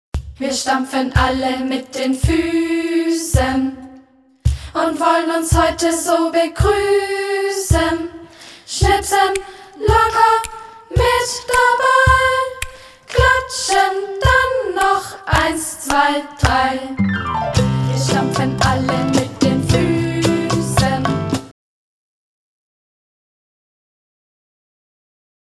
Gattung: Sing- und Sprechkanons für jede Gelegenheit
Besetzung: Gesang Noten